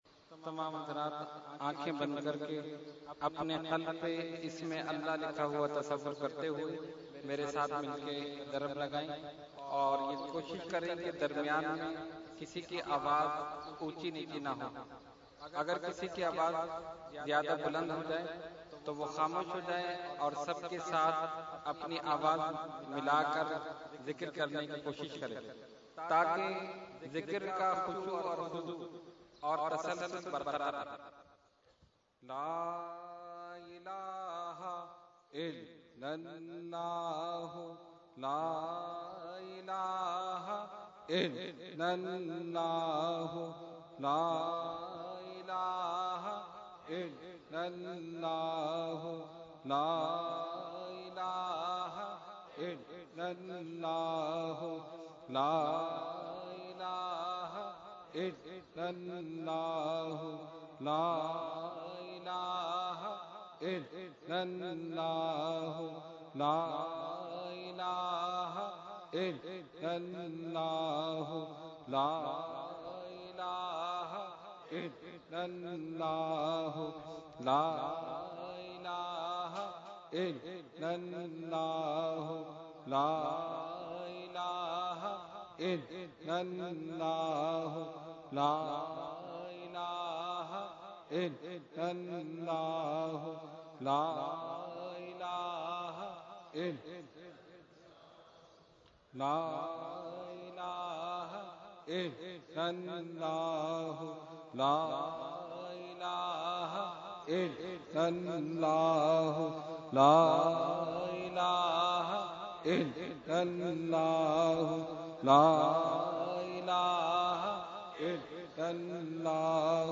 Category : Zikar | Language : ArabicEvent : Urs e Qutb e Rabbani 2013